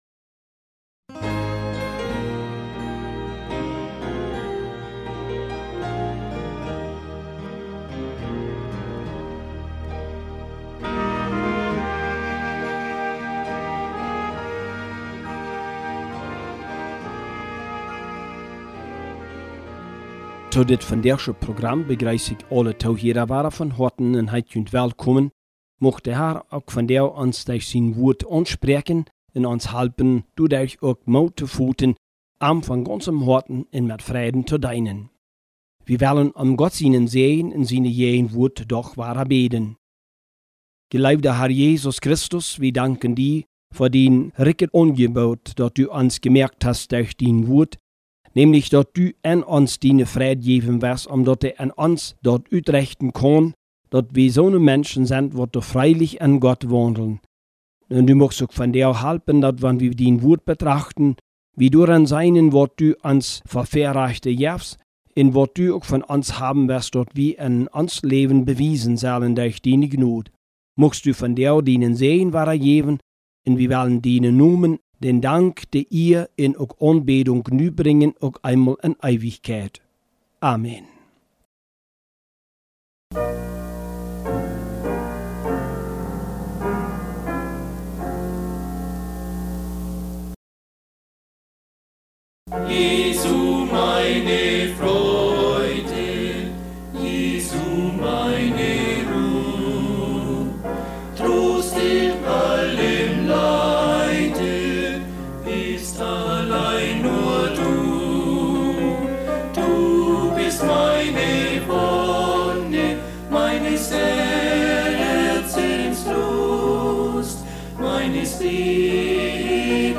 PLATDEUTSCHE Predigten Habakuk https